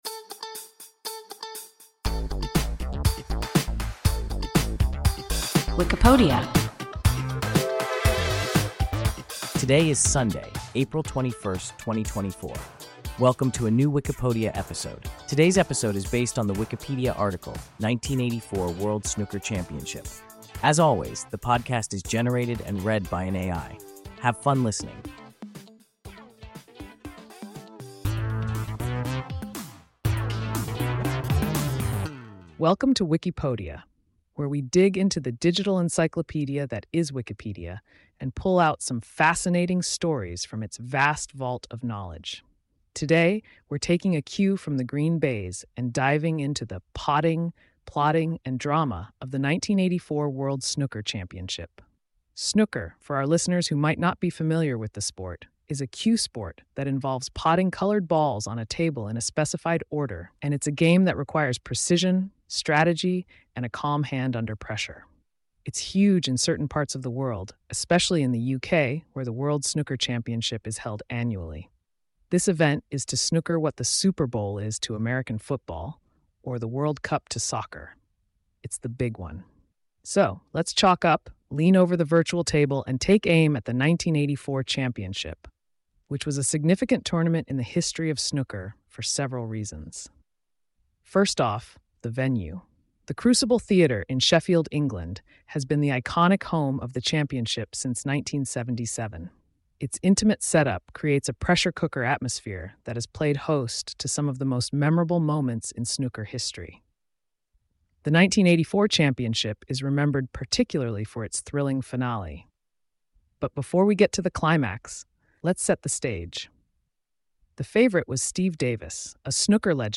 1984 World Snooker Championship – WIKIPODIA – ein KI Podcast